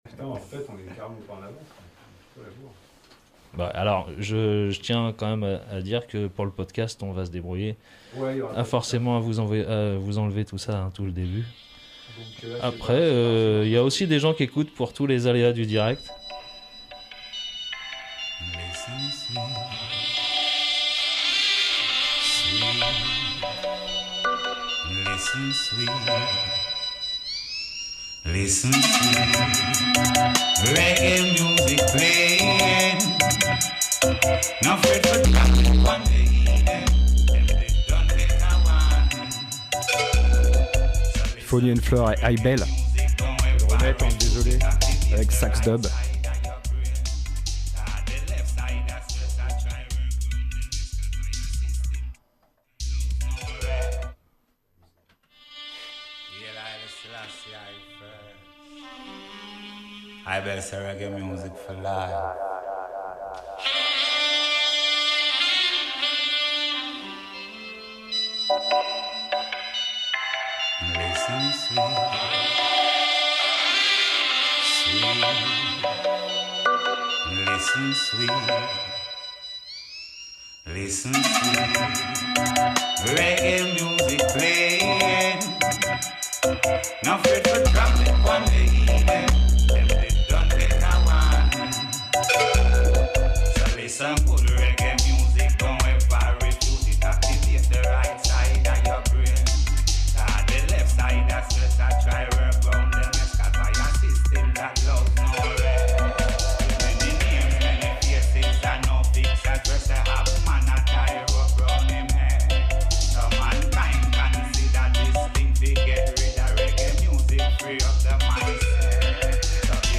Roots Dub 'n Kultcha !!
************************************************** EMISSION du 05 02 13 : Encore une fois nos excuses pour les couics et les couacs !!
...du vinyle, des craquements , ...Direct live from HDR Bonne écoute !